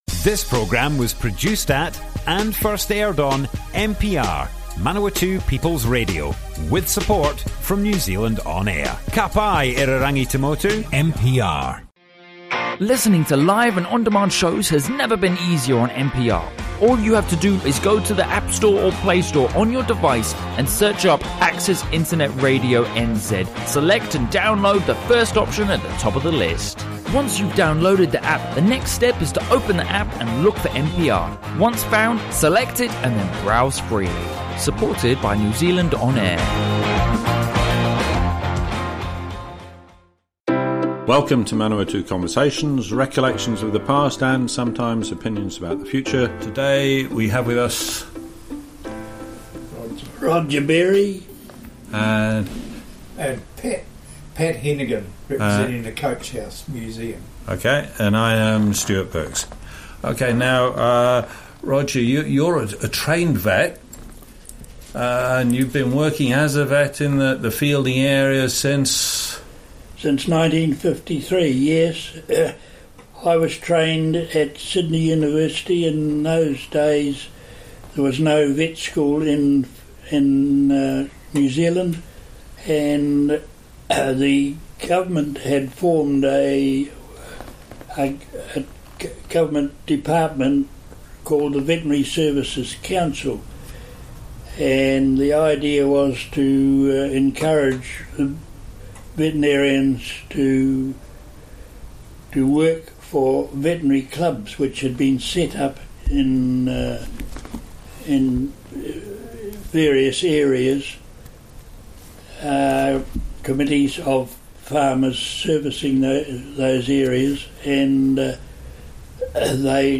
Manawatu Conversations More Info → Description Broadcast on Manawatu People's Radio 19th February 2019.
oral history